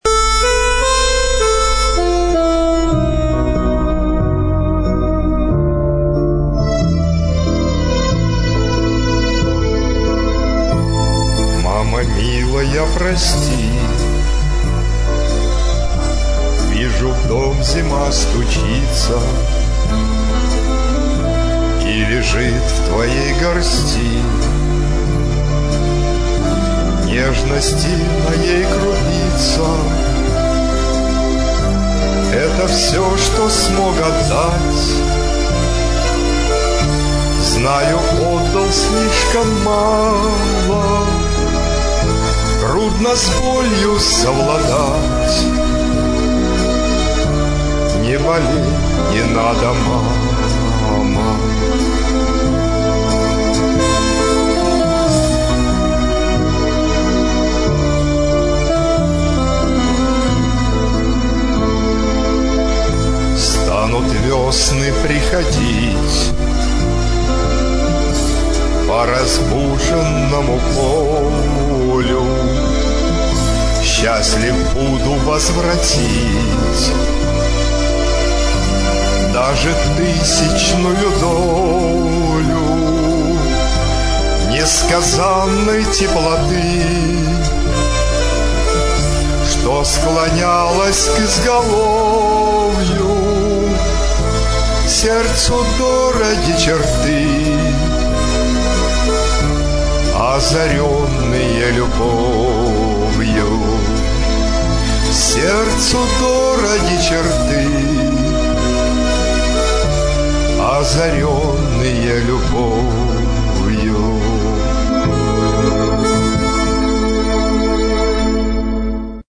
• Жанр: Авторская песня